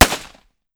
45 ACP 1911 Pistol - Gunshot A 001.wav